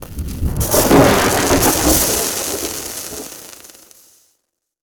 elec_lightning_magic_spell_03.wav